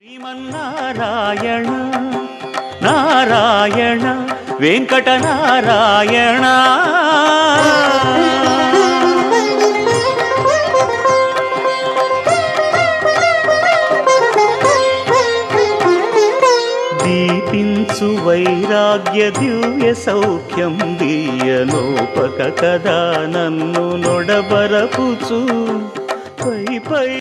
Keerthana
best flute ringtone download